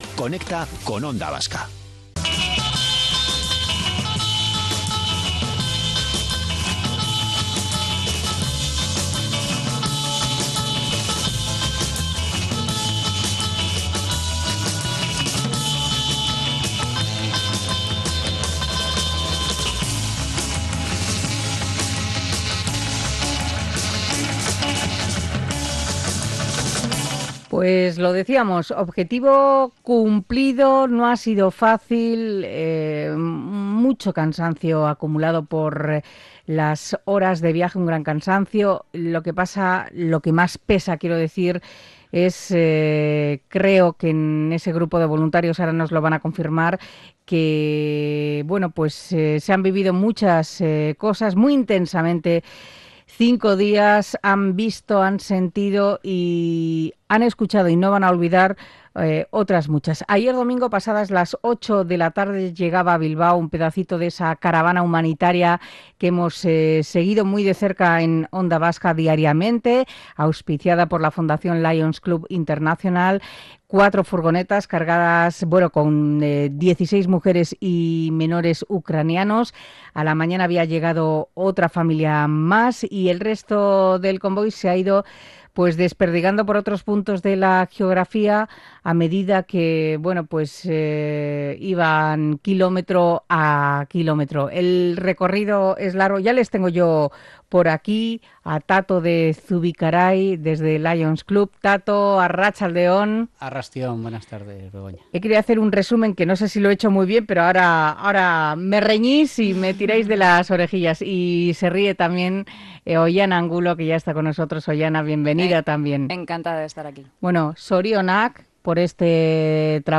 Compañía y entretenimiento con los oyentes en el centro.